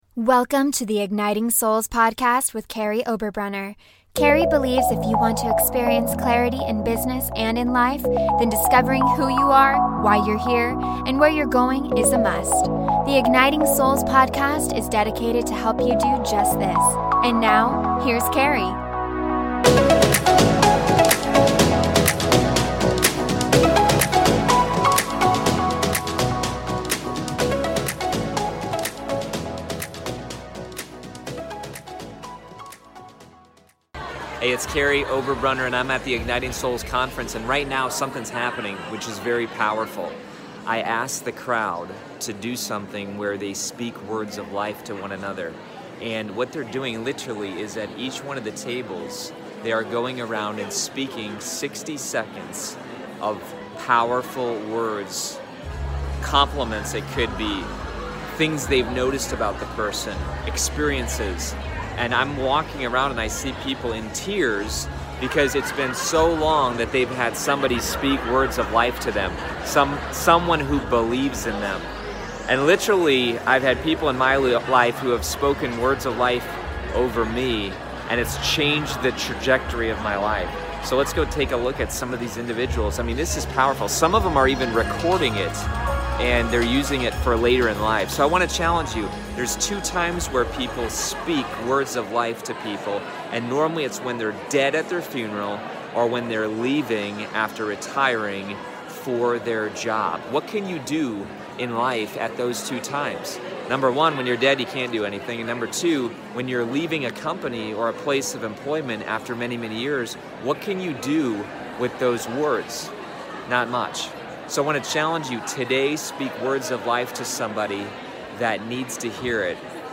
I'm at the Igniting Souls Conference, and I asked the crowd to speak words of life to one another.